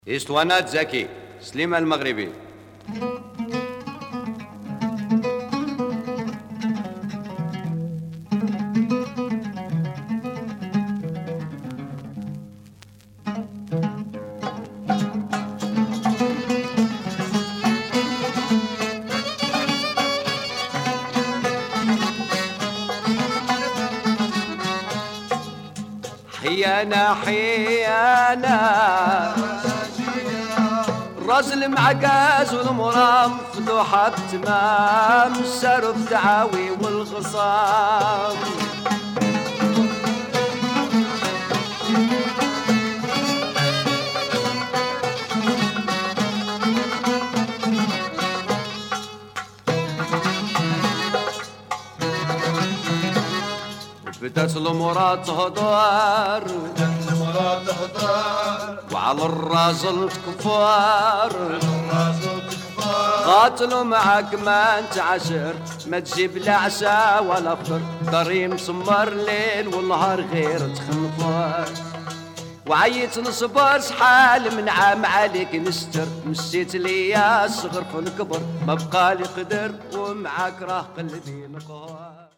Moroccan song